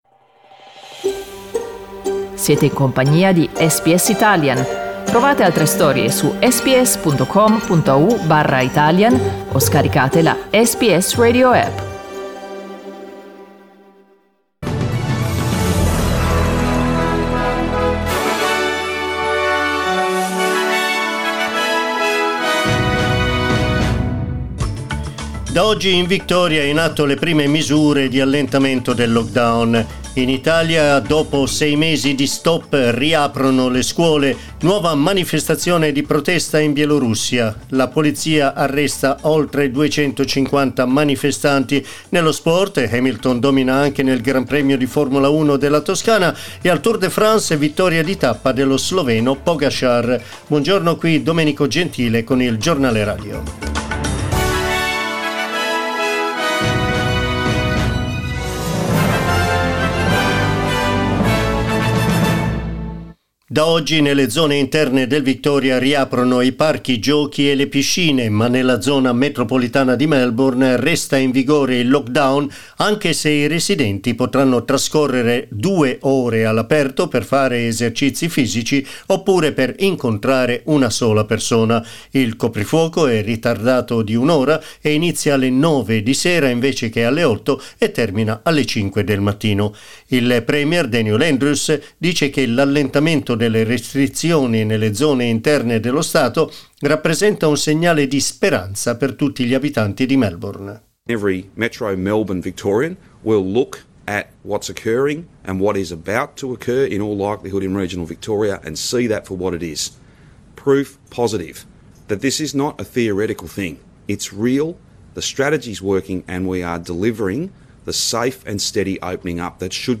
The Italian news bulletin that went to air this morning on SBS Radio at 09:00 am.